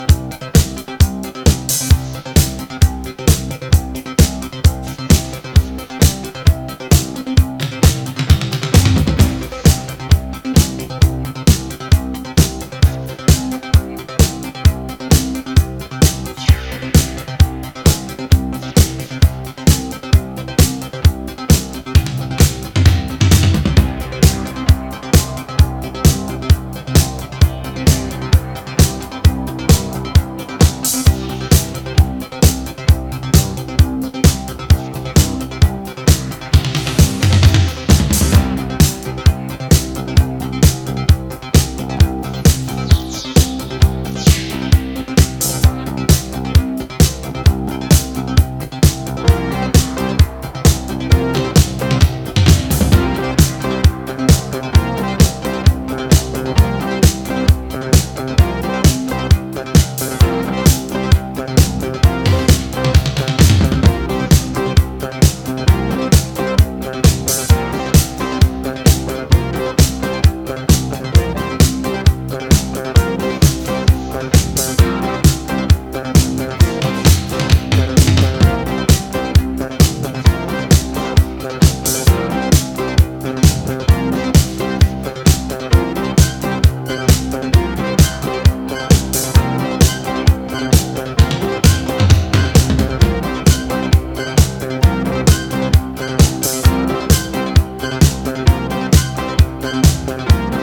インストのコズミック・ハイエナジー